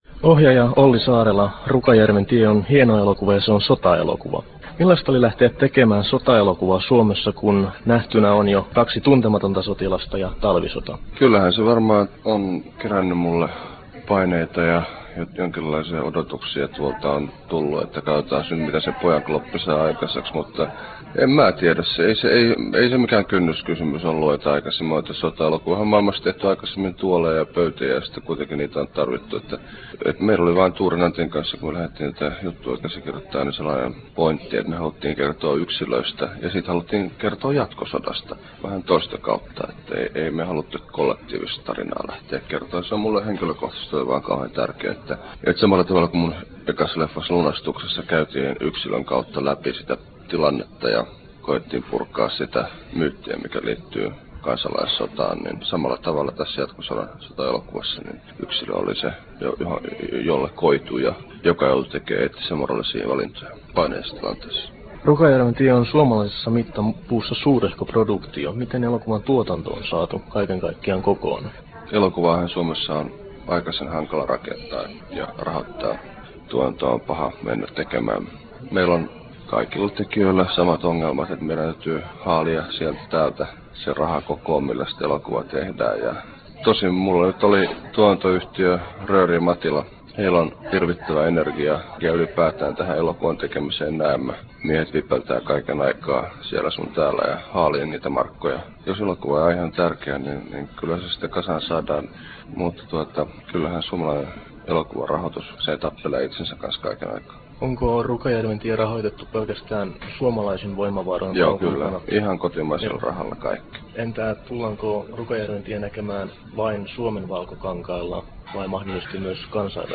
Olli Saarela ja Rukajärven tie • Haastattelut
Nauhoitettu Turussa 20.01.1999